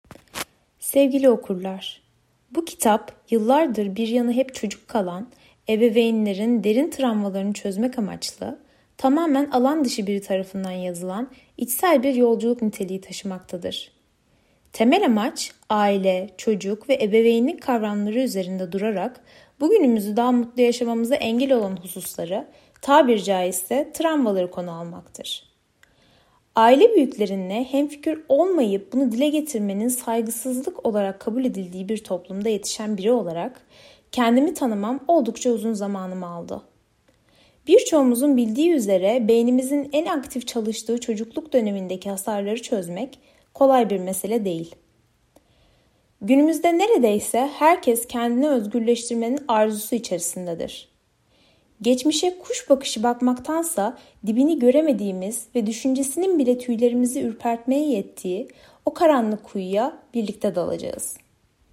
Turkish_索必达配音